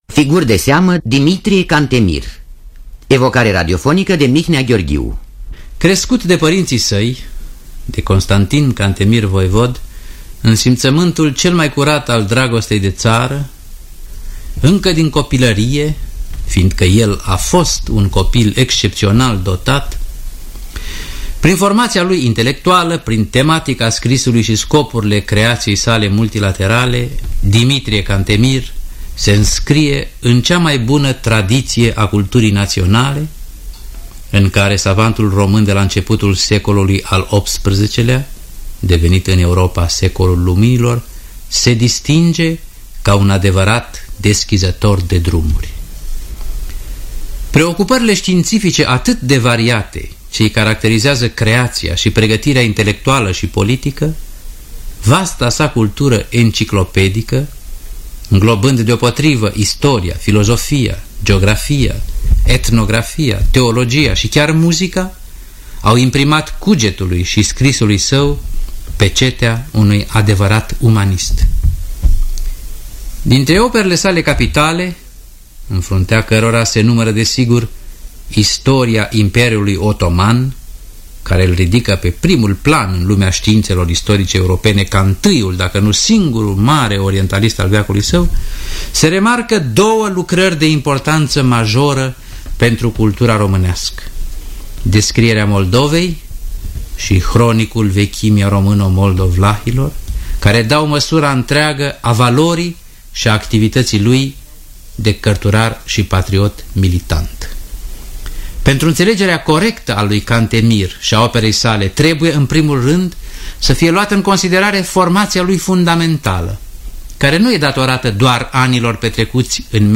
Scenariul radiofonic de Mihnea Gheorghiu.